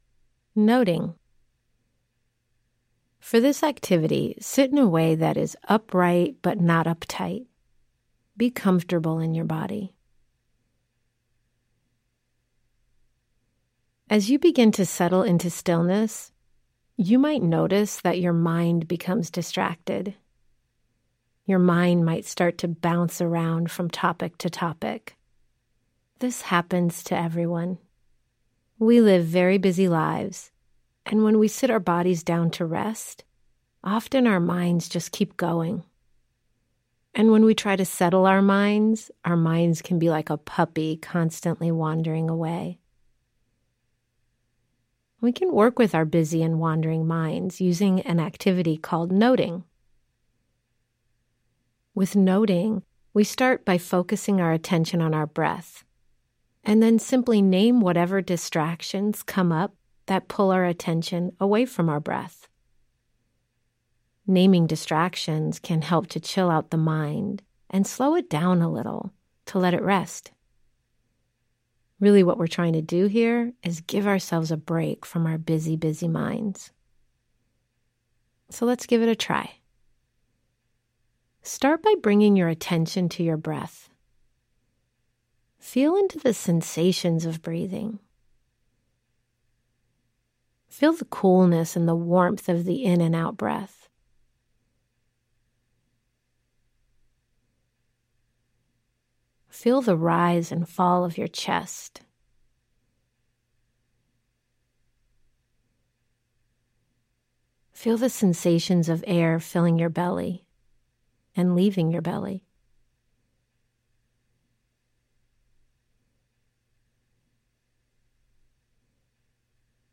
Guided Meditations & Mindfulness